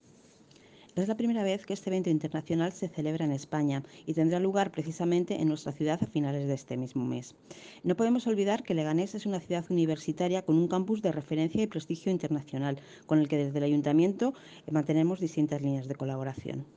Audio-Virginia-Jimenez-concejala-de-Educacion.mp3